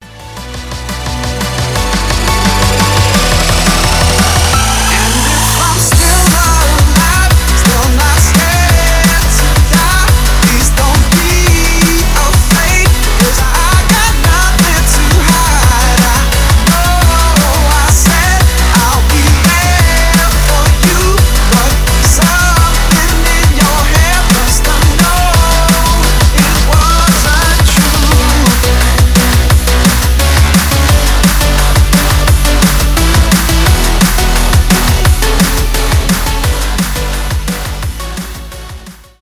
electronic
драм энд бейс , мужской голос , громкие , дабстеп